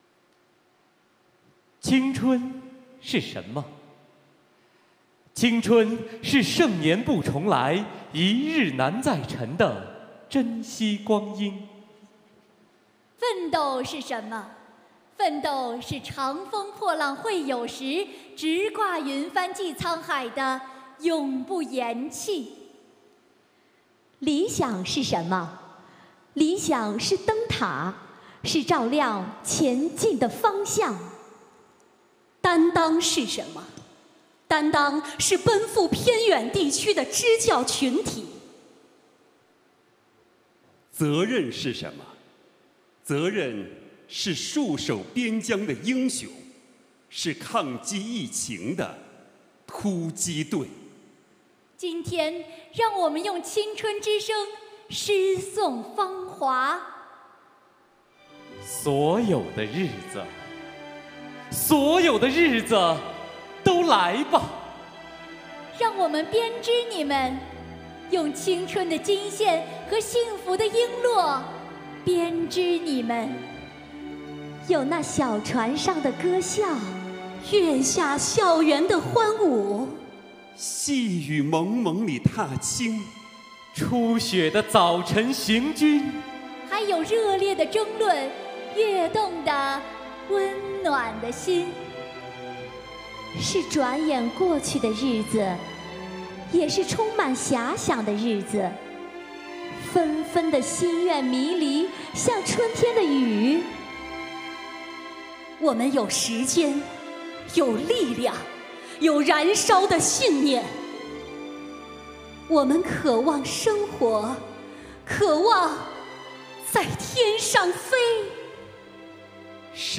诵读征集活动优秀作品